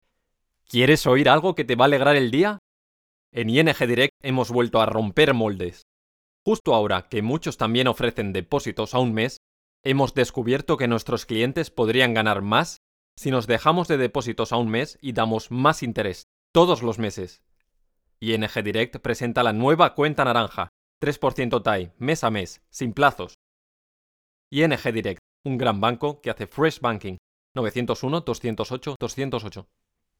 I deliver best results with my very confident, smooth and professional tone for narration and technical copy. Also, I sound friendly, warm, sincere, honest and natural.
kastilisch
Sprechprobe: Industrie (Muttersprache):